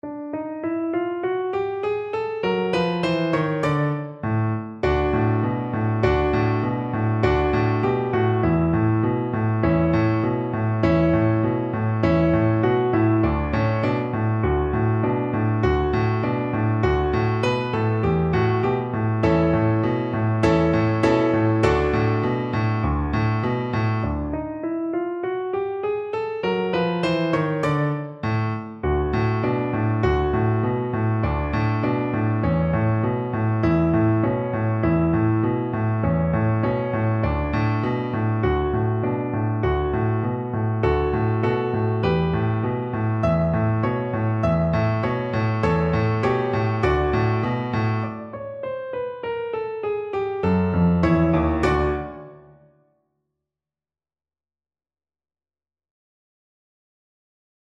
2/4 (View more 2/4 Music)
Energico
Pop (View more Pop Cello Music)